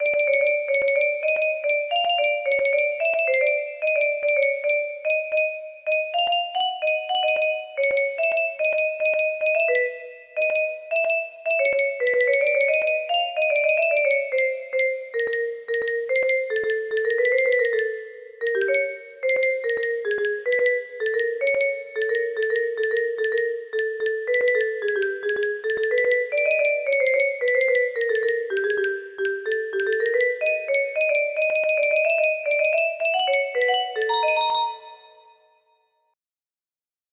HOLY SHIT ITS A CHASE!!!! music box melody